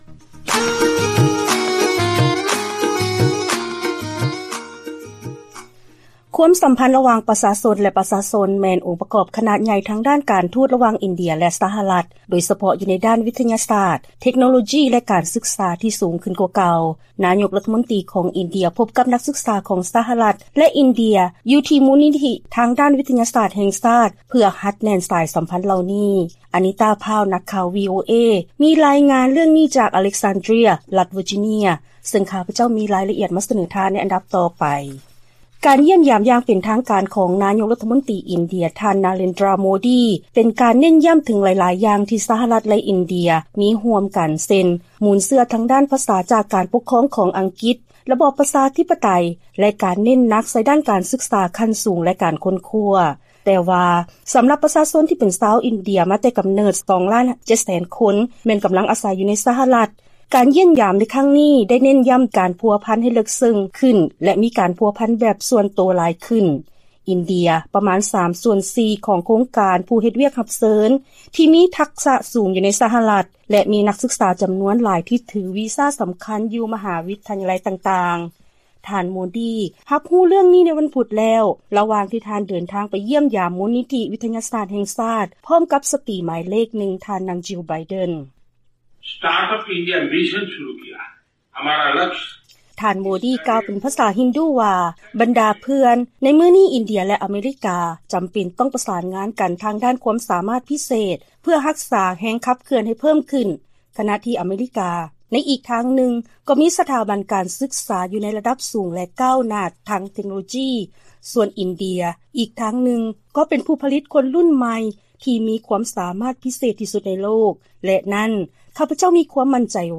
Narendra Modi, Indian Prime Minister, Hindi
Rep. Ro Khanna, Democrat